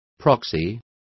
Complete with pronunciation of the translation of proxy.